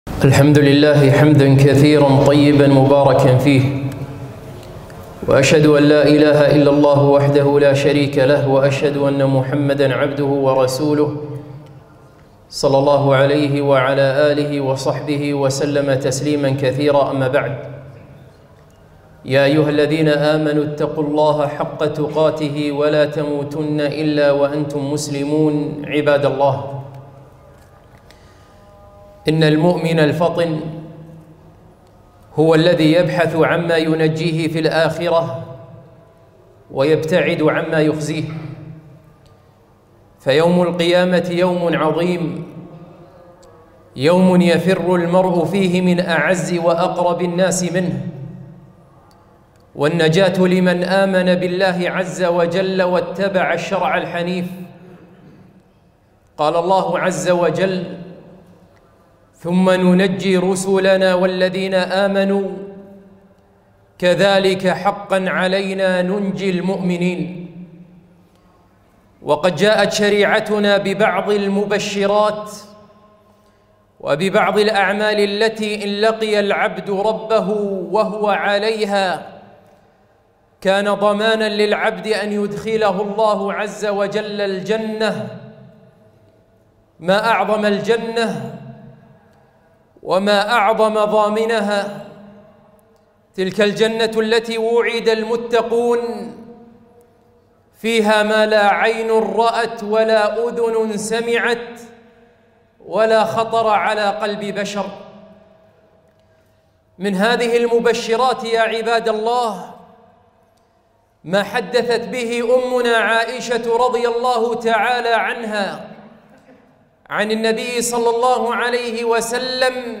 خطبة - أين أنت من هذه الست؟